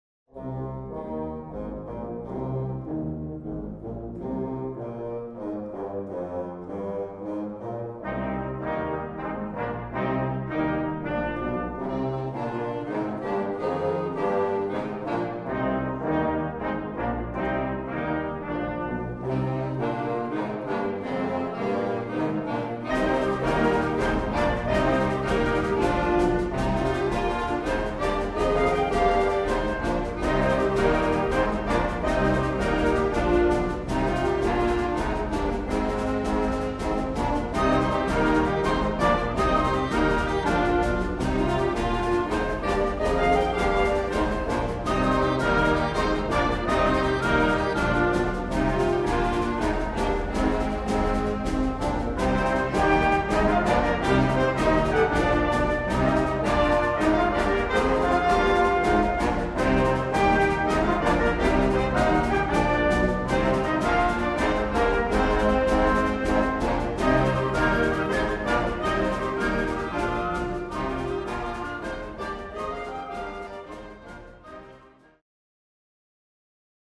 Puhallinorkesteri / Wind Orchestra Grade 3-5